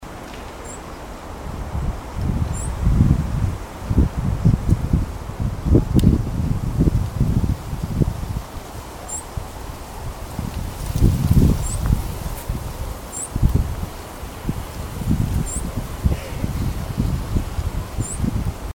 Bico-de-pimenta-chaquenho (Saltatricula multicolor)
Nome em Inglês: Many-colored Chaco Finch
Fase da vida: Adulto
Localidade ou área protegida: Reserva Ecológica Costanera Sur (RECS)
Condição: Selvagem
Certeza: Fotografado, Filmada, Gravado Vocal